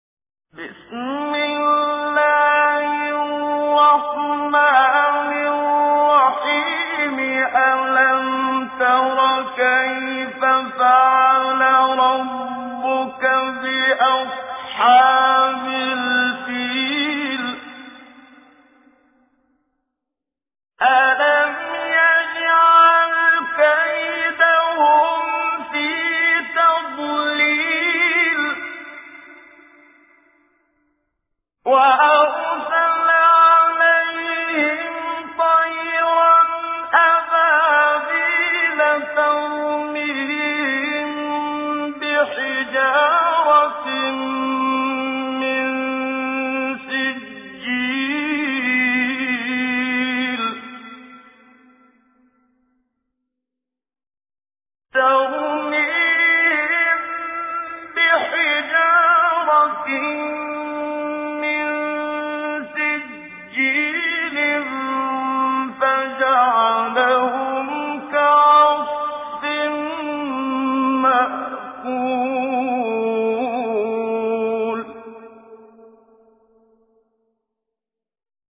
تجويد
سورة الفيل الخطیب: المقريء الشيخ محمد صديق المنشاوي المدة الزمنية: 00:00:00